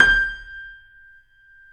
Index of /90_sSampleCDs/Roland - Rhythm Section/KEY_YC7 Piano ff/KEY_ff YC7 Mono